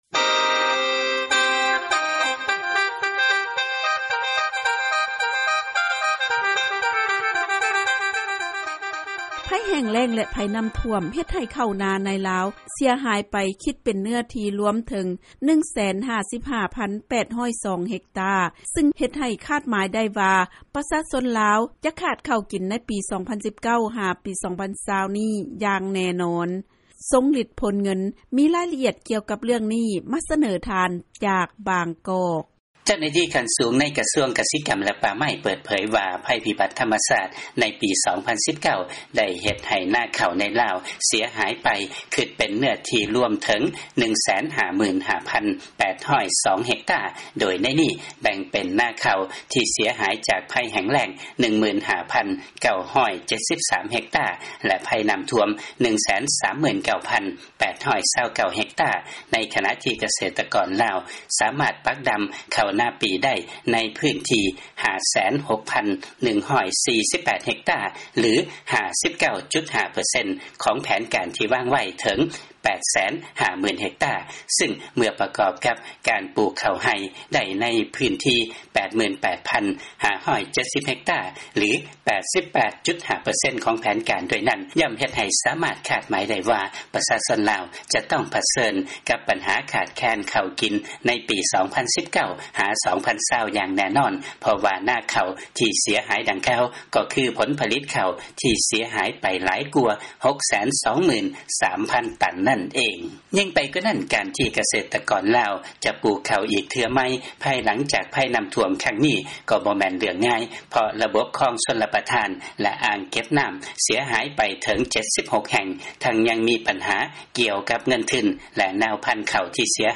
ເຊີນຟັງລາຍງານ ໄພແຫ້ງແລ້ງ ແລະໄພນ້ຳຖ້ວມເຮັດໃຫ້ ດິນນາ ໃນລາວ ເສຍຫາຍຫຼວງຫຼາຍ ຊຶ່ງ ປຊຊ ຈະຂາດເຂົ້າກິນ ໃນປີນີ້ ຈົນເຖິງປີໜ້າ